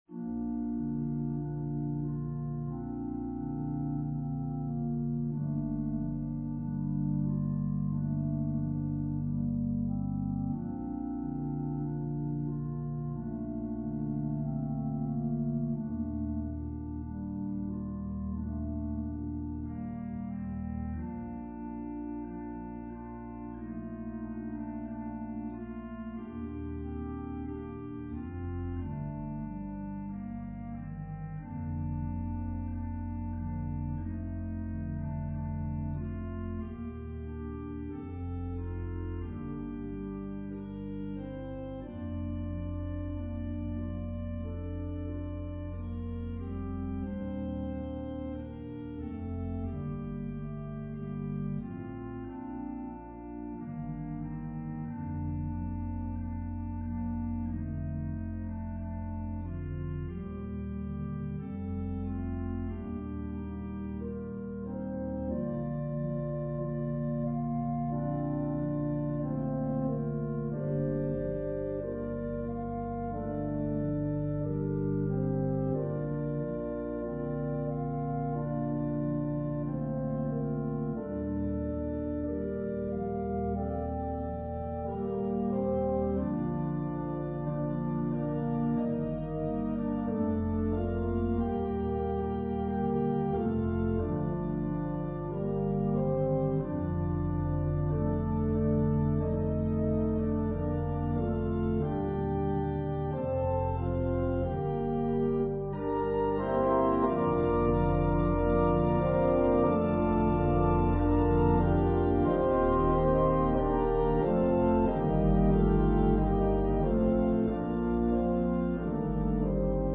An organ solo version
Voicing/Instrumentation: Organ/Organ Accompaniment